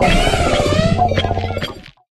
Cri de Roue-de-Fer dans Pokémon HOME.